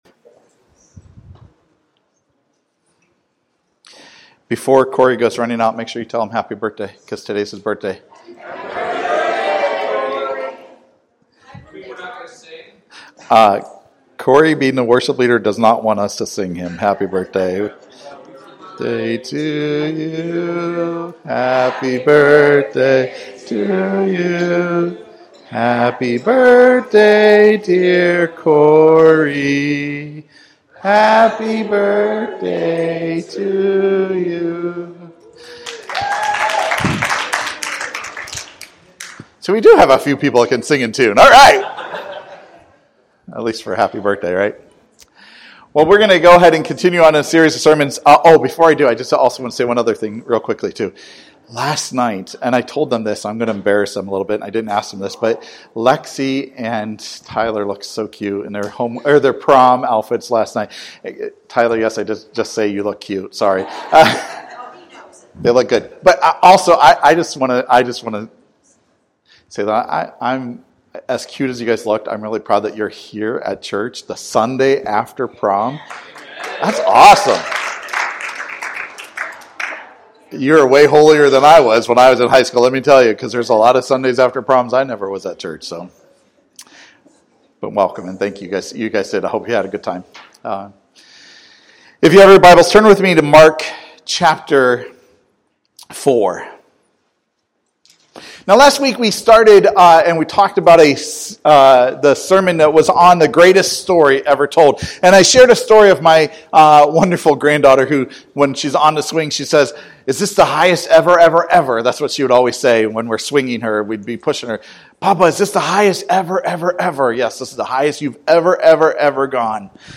Sermons by Passion Community Church
Sermons by Passion Community Church … continue reading 299 episodes # Religion # Sunday Service # Passion Community Church # Christianity